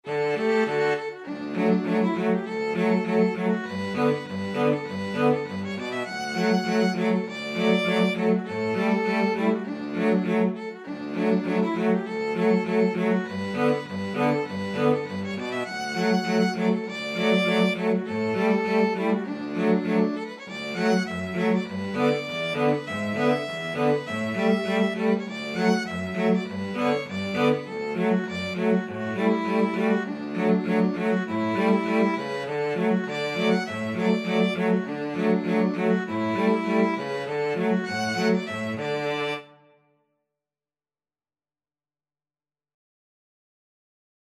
D major (Sounding Pitch) (View more D major Music for Violin-Cello Duet )
2/4 (View more 2/4 Music)
Allegro (View more music marked Allegro)
Violin-Cello Duet  (View more Easy Violin-Cello Duet Music)
Traditional (View more Traditional Violin-Cello Duet Music)